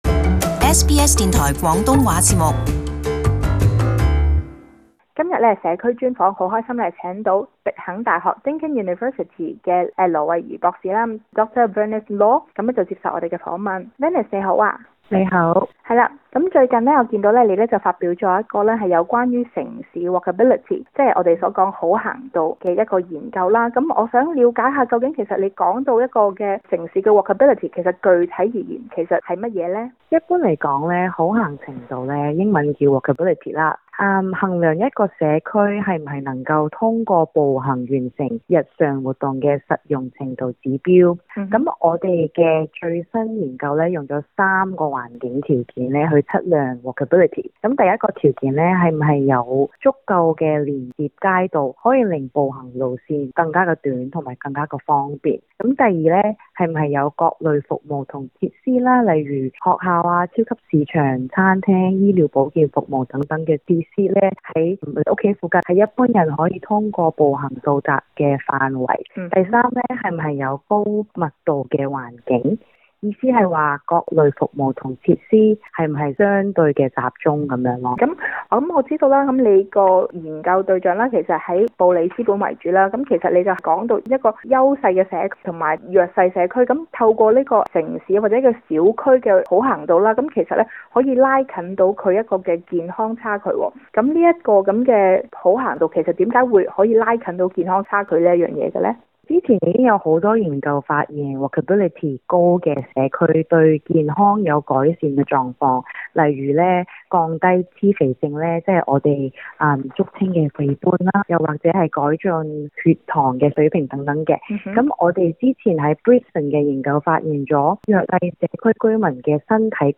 【社區專訪】知「足」者「行」亦樂